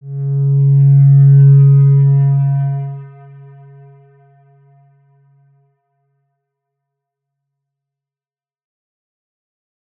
X_Windwistle-C#2-mf.wav